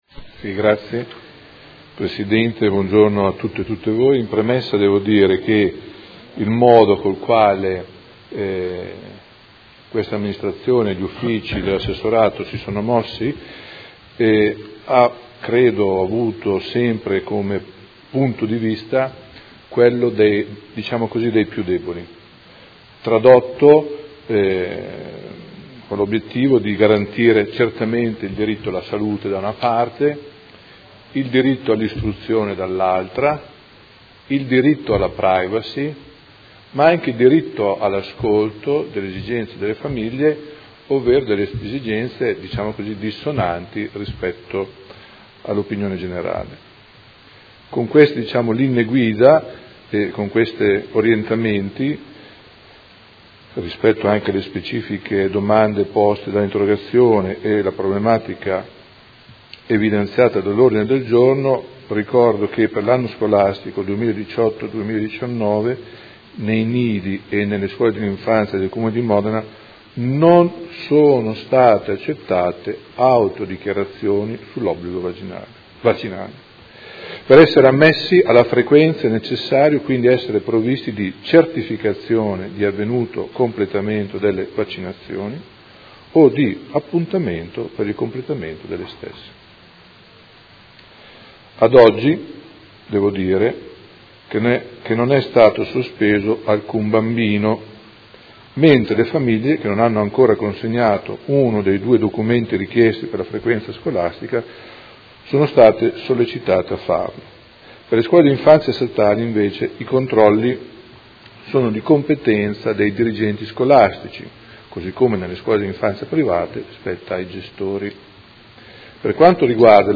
Seduta del 11/10/2018. Dibattito su Ordine del Giorno del Consigliere Galli (F.I.) avente per oggetto: Il Comune si opponga alla volontà del Governo di consentire ai genitori l’iscrizione a scuola dei loro figli presentando una semplice autocertificazione che attesti l’obbligo vaccinale, e interrogazione delle Consigliere Venturelli e Baracchi (PD) avente per oggetto: Caos autocertificazioni dei vaccini.